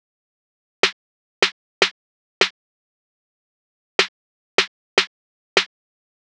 Snare2.wav